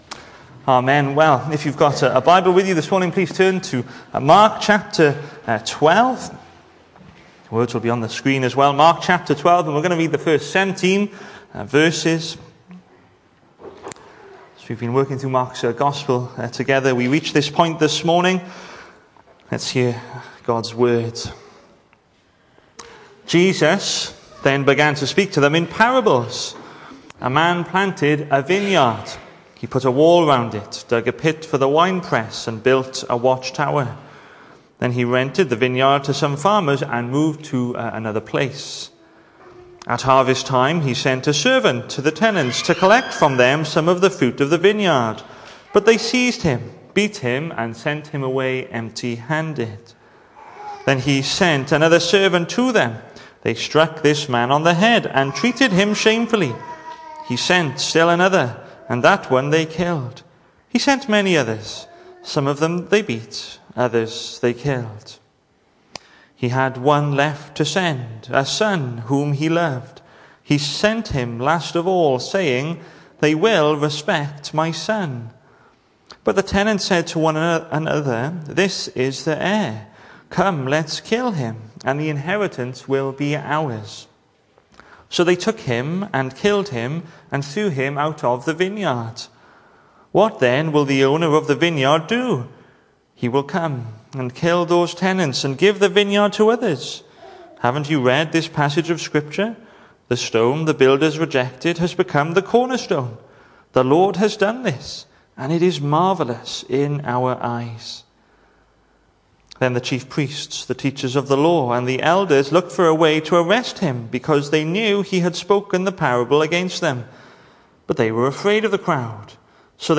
Hello and welcome to Bethel Evangelical Church in Gorseinon and thank you for checking out this weeks sermon recordings.
The 25th of May saw us host our Sunday morning service from the church building, with a livestream available via Facebook.